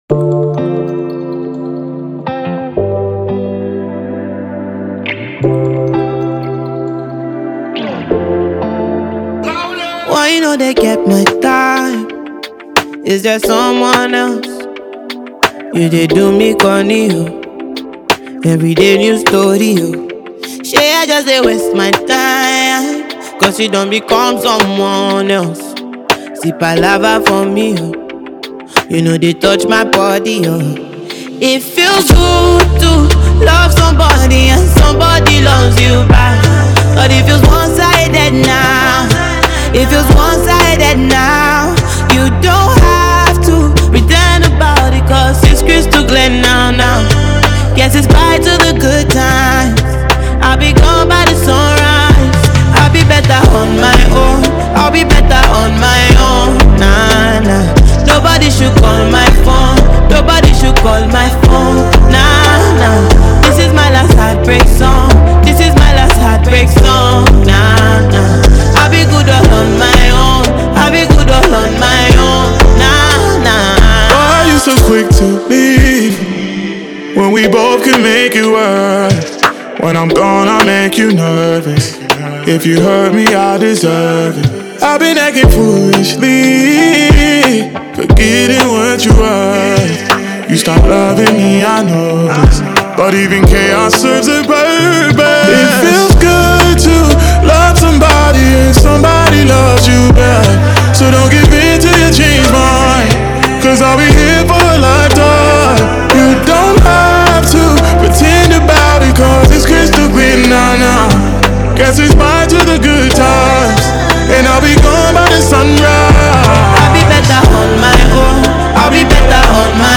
heart-warming track
African Music Genre: Afrobeats Released